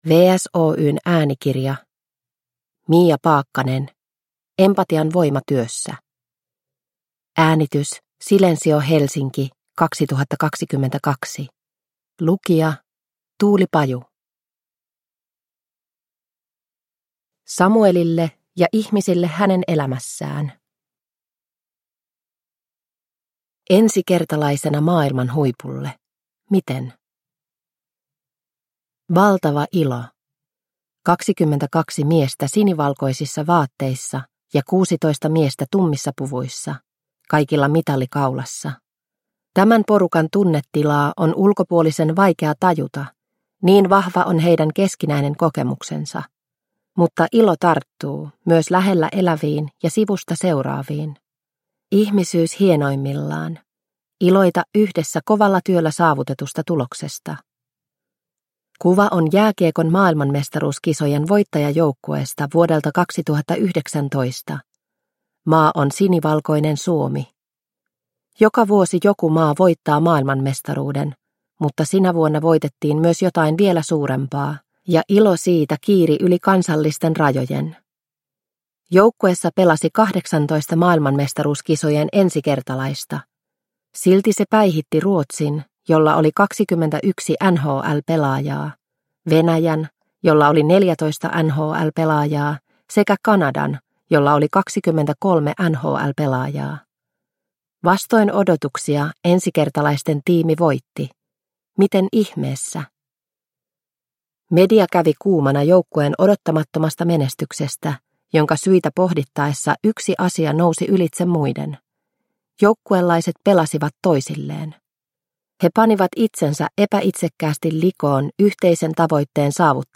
Empatian voima työssä – Ljudbok – Laddas ner